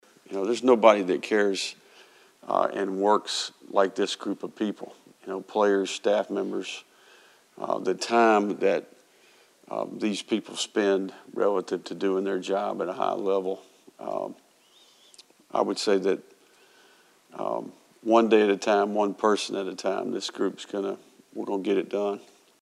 Florida Gators head coach Billy Napier took to the podium Monday to address the team’s loss to Vanderbilt on Saturday.